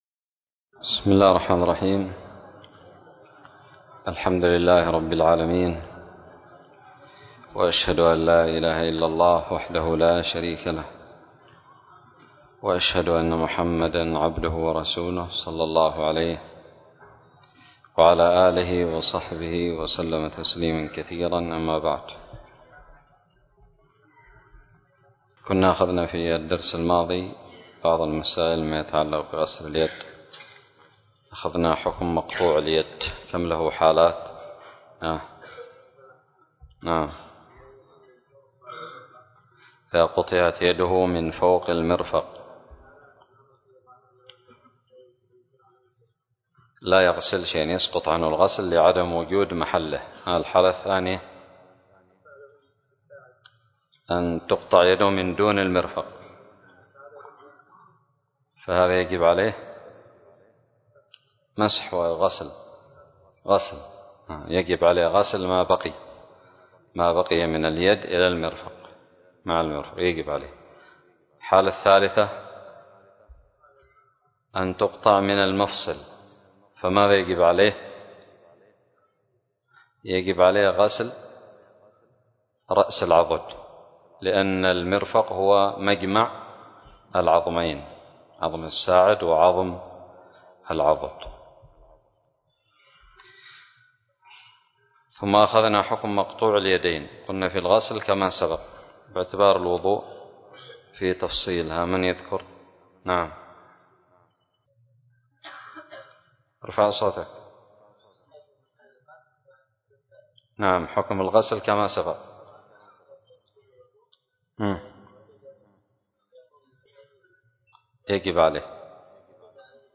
الدرس الثامن عشر بعد المائة من كتاب الطهارة من كتاب المنتقى للمجد ابن تيمية
ألقيت بدار الحديث السلفية للعلوم الشرعية بالضالع